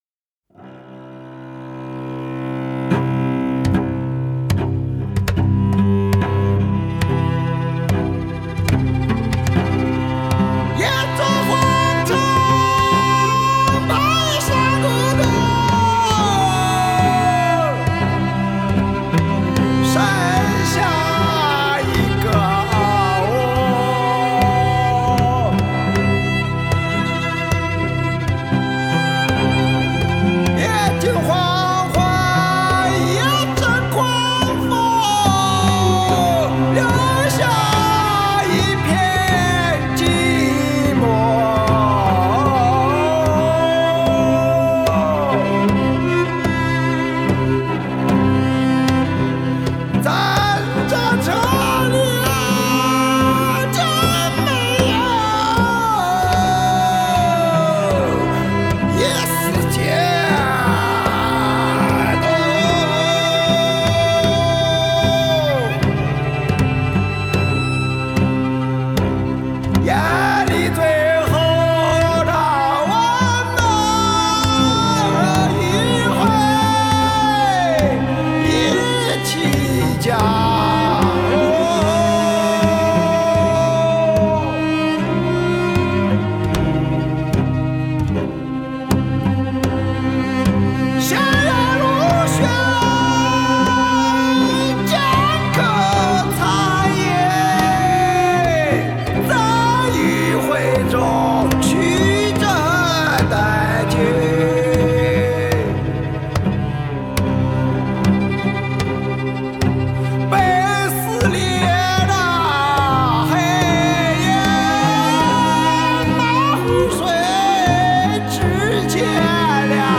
Ps：在线试听为压缩音质节选，体验无损音质请下载完整版
大提琴